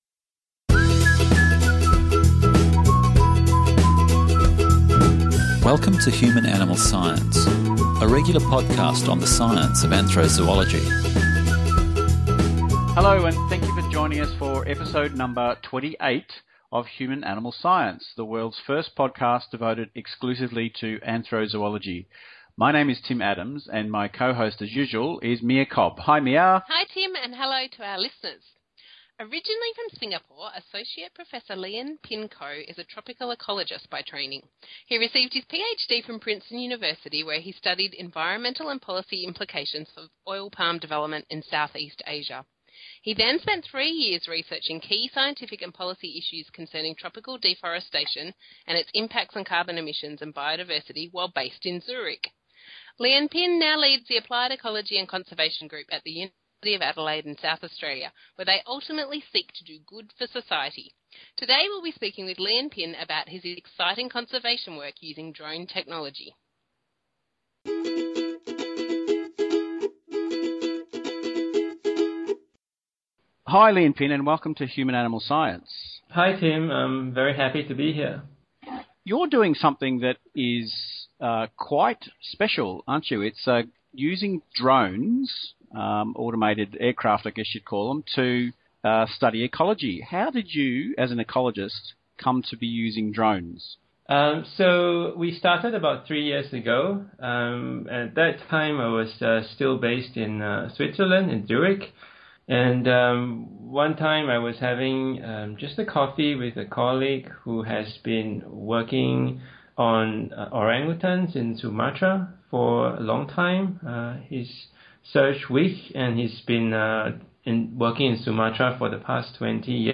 This interview was fascinating, and the topic complex, so we are releasing it in two parts.